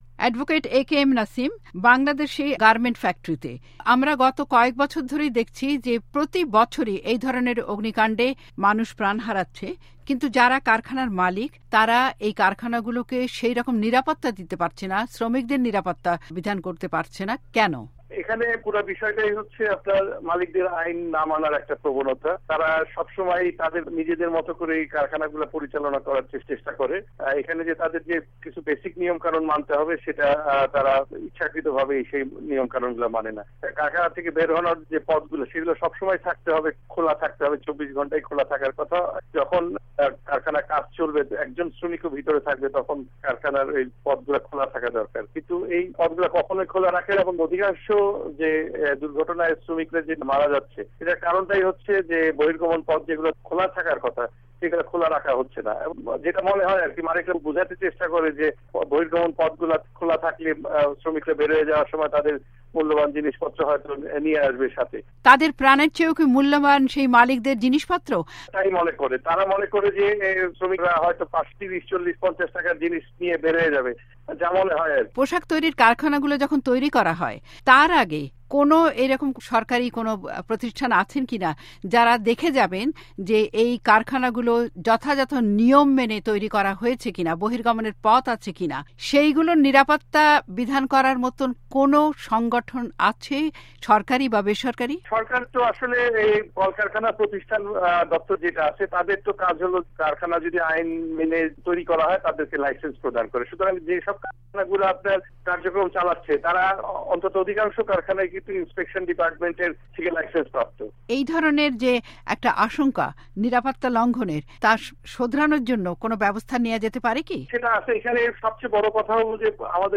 সাক্ষাত্কার